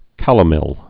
(kălə-mĕl, -məl)